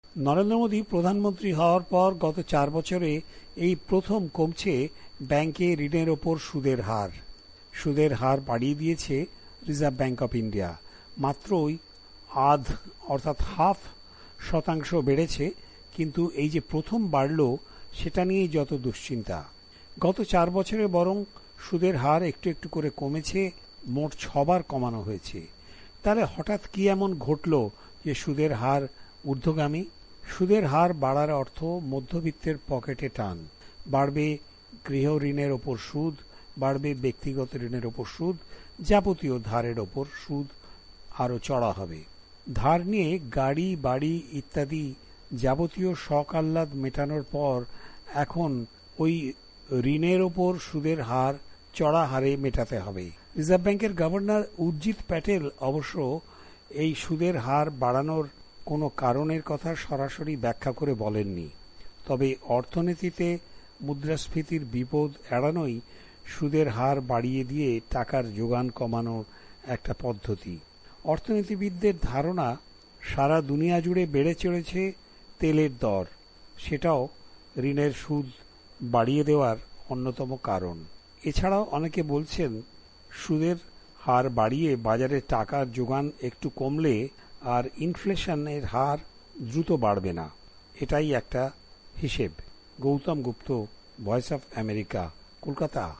রিপোর্ট (সুদের হার)